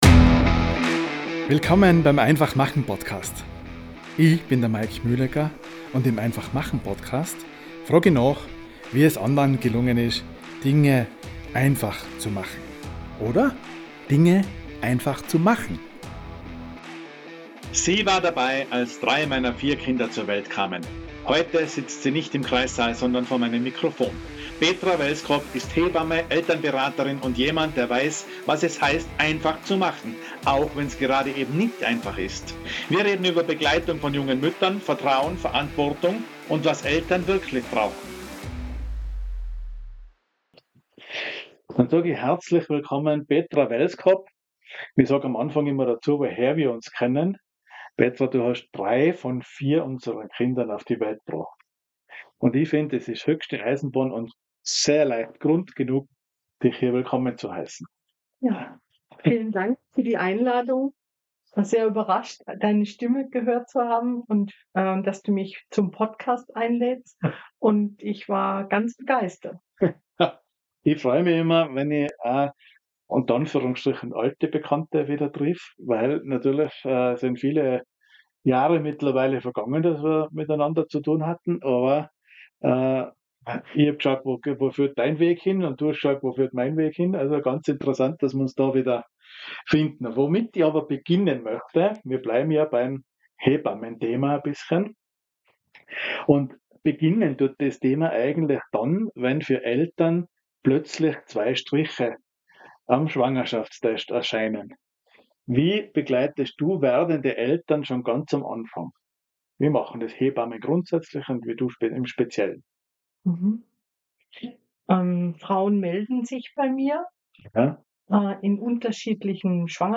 Heute war sie bei mir im Podcast-Studio. Und wir haben geredet – über die kleinen und großen Wunder. Aber auch über die Zweifel, die Tränen und den Druck, alles „richtig“ zu machen.